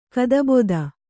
♪ kadaboda